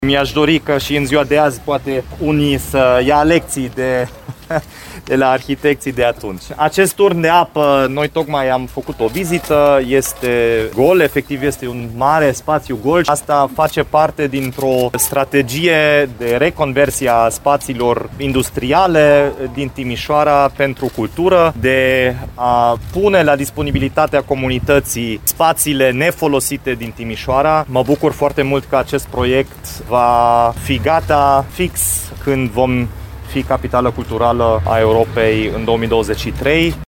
Lucrările de refuncționalizare a edificiului vor începe la toamnă și ar trebui să fie gata până când Timișoara va fi Capitală Europeană a Culturii, a spus primarul Dominic Fritz.
09-dominic-fritz-turn-de-apa.mp3